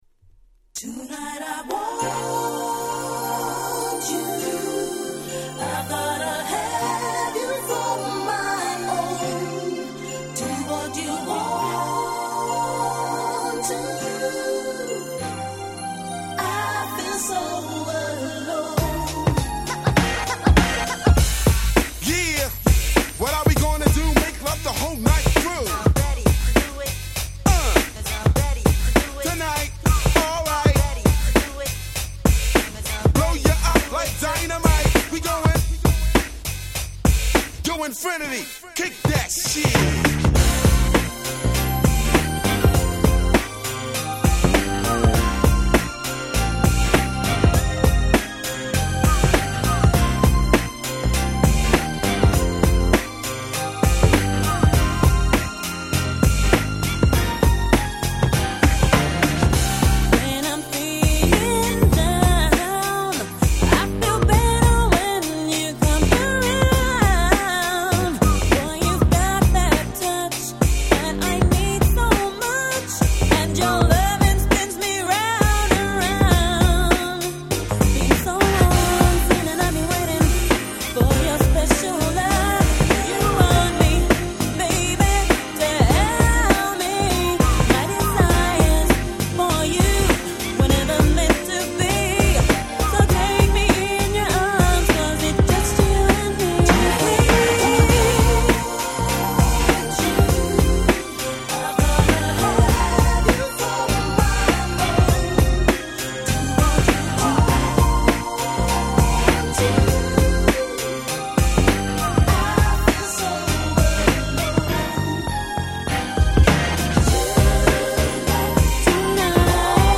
Nice UK R&B !!!
哀愁漂うGroovyでSmoothな素晴らしい1曲。
90's R&Bが好きなら撃沈必至。